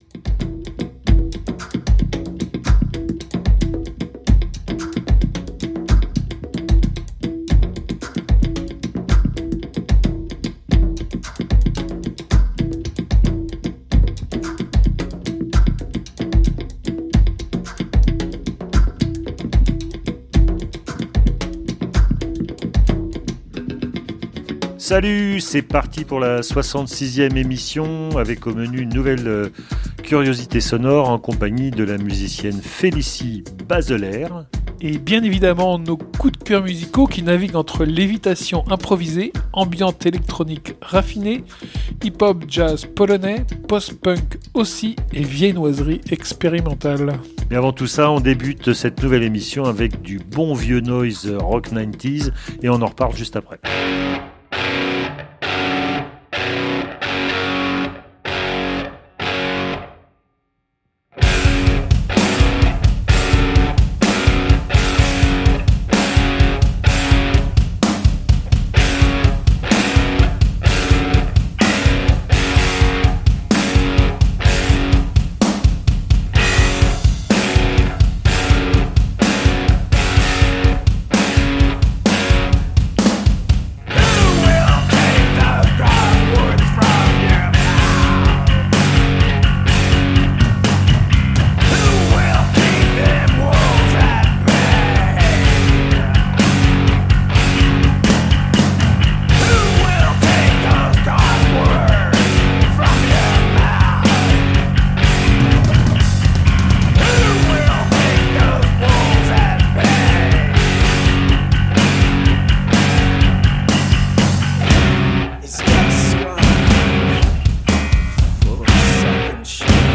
Playlist musicale dans l’ordre chronologique